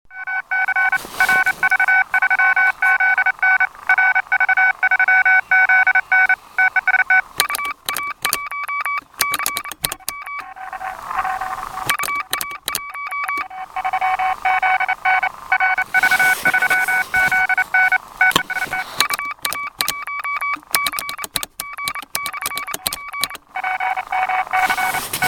честный QRP сигнал... Ясно как- словно передатчика у меня нет вообще.
Правда и прохождение было неважное.